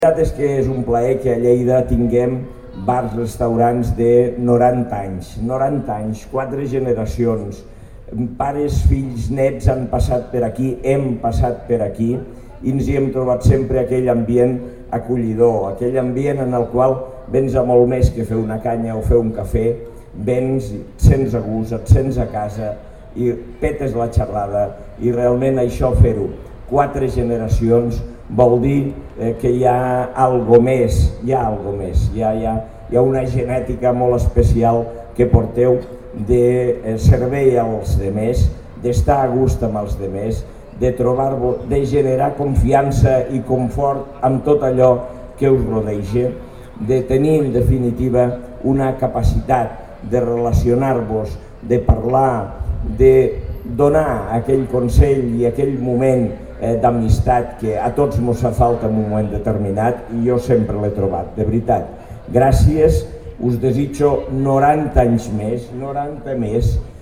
tall-de-veu-de-lalcalde-angel-ros-sobre-els-90-anys-del-bar-restaurant-comba-de-cappont